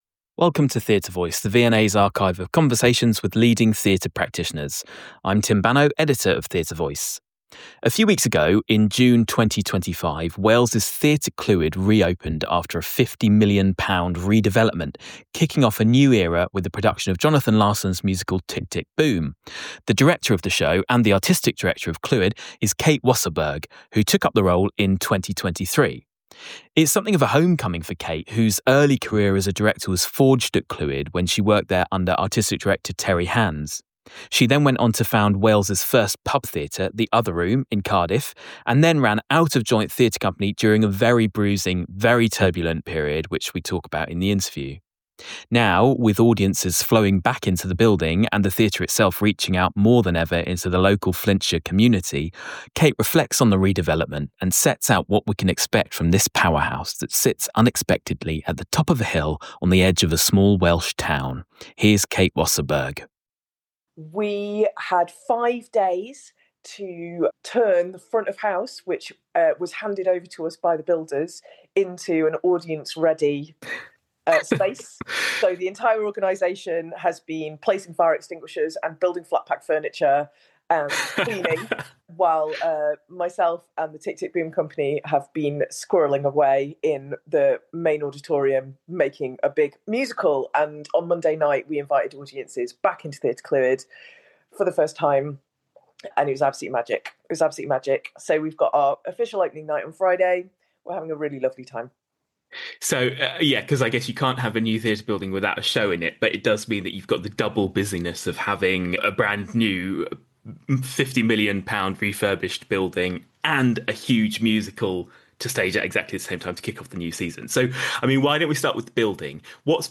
INTERVIEW
Recorded on Zoom, 4th June, 2025